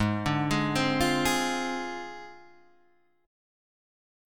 G# 6th Flat 5th